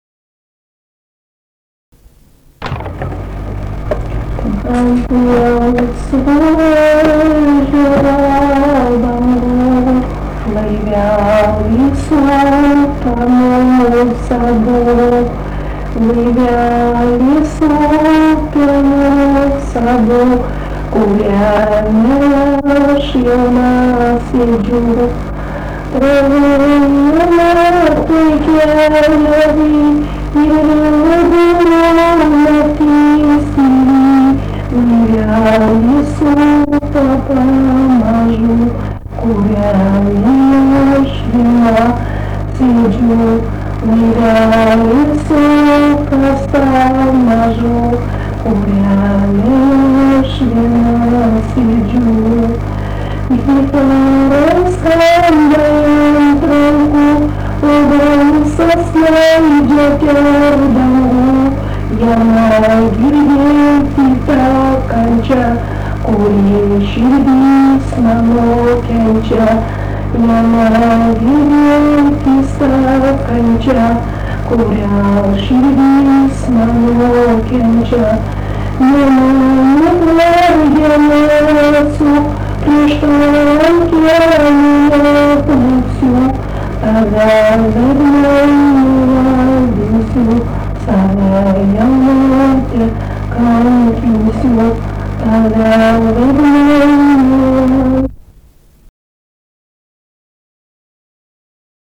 romansas
Mančiai
vokalinis